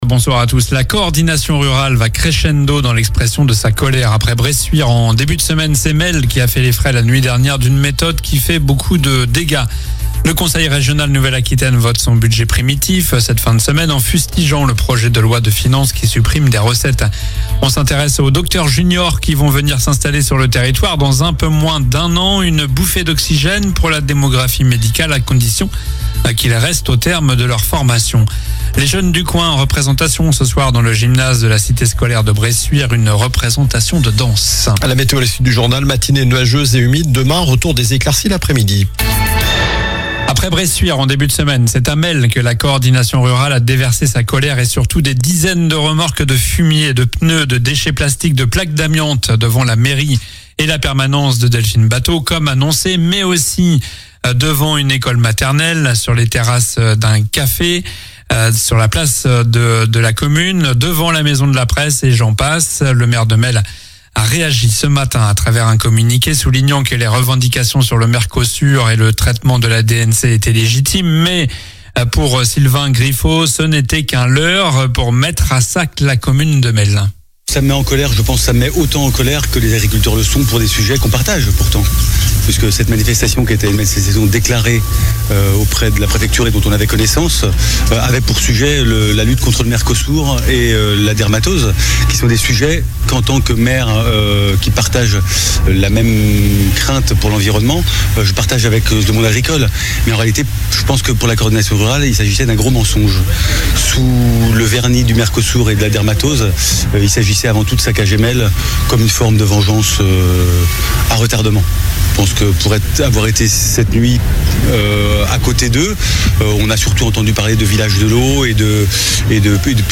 Journal du jeudi 18 décembre (soir)